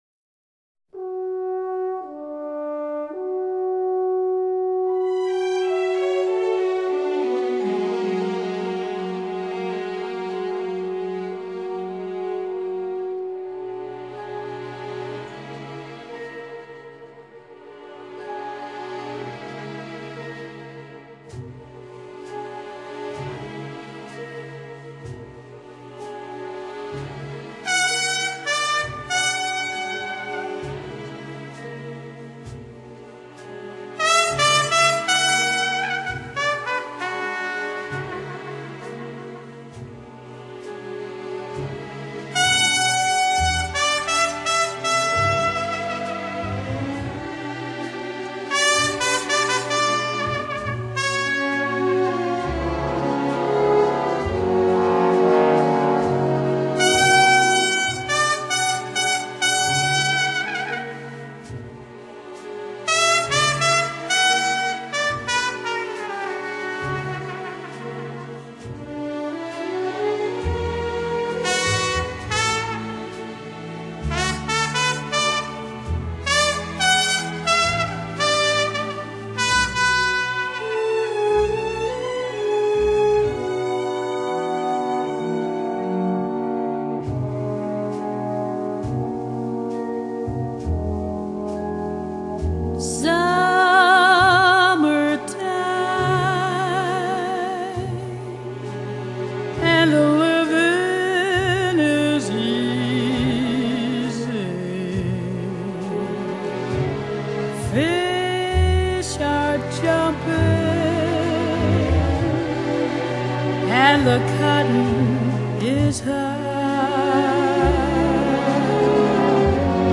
全球两大定位最佳零失真至专业麦克风录音